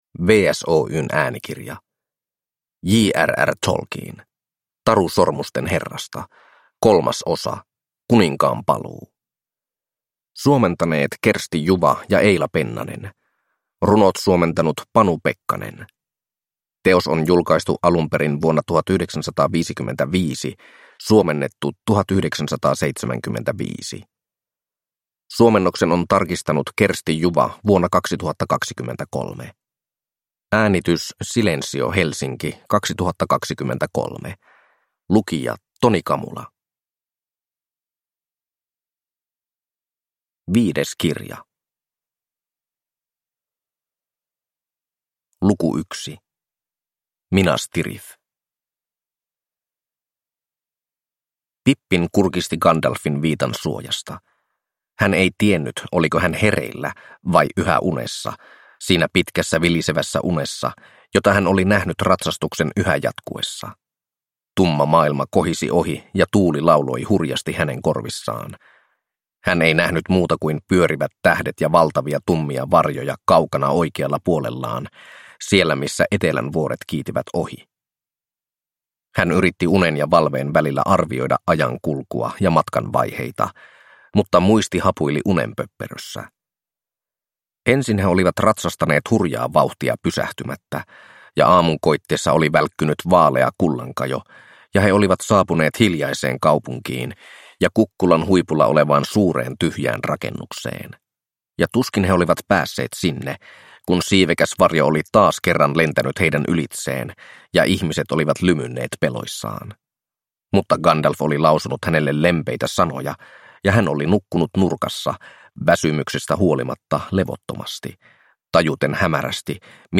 Taru Sormusten herrasta 3: Kuninkaan paluu (tarkistettu suomennos) – Ljudbok